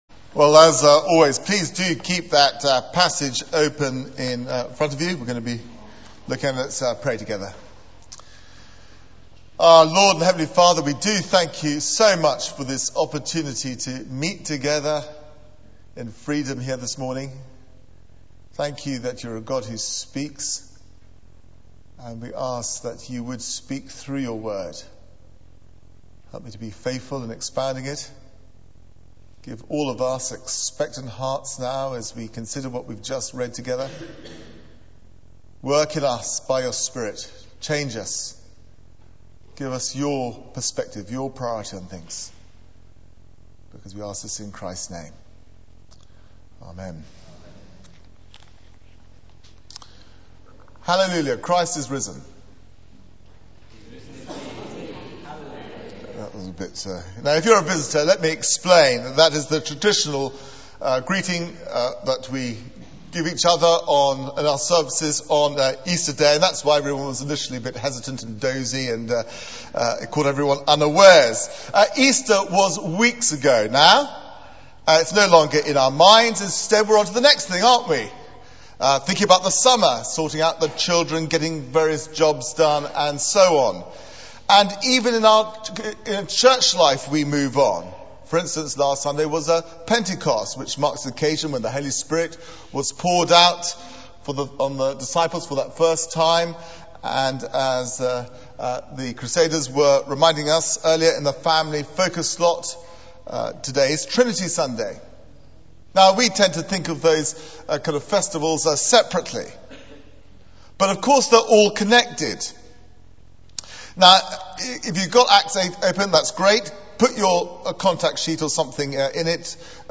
Media for 9:15am Service on Sun 07th Jun 2009 09:15 Speaker: Passage: Acts 8: 1 - 8 Series: Foundations for World Mission Theme: Moving Out Sermon Search the media library There are recordings here going back several years.